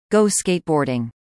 19. go skateboarding /ɡoʊ//ˈskeɪtˌbɔːr.dɪŋ/ : đi trượt ván